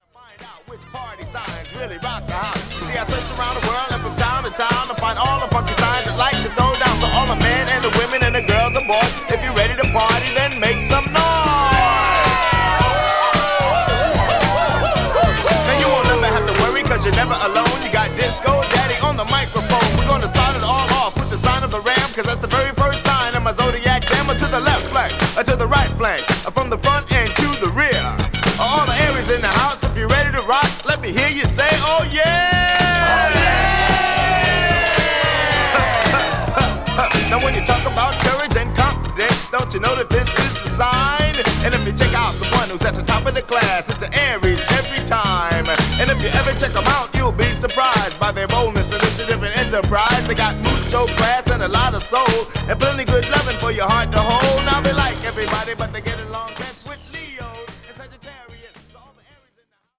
1983年の西海岸オールドスクール/ディスコ・ラップ。